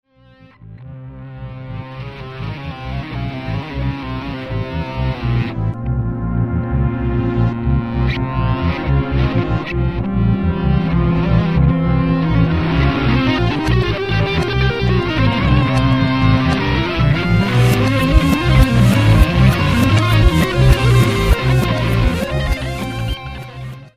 Ein Flug auf diesem feinen Orientteppich würde mir auch gefallen.
Ach ja, ich habe es dann auch mal rückwärts gehört,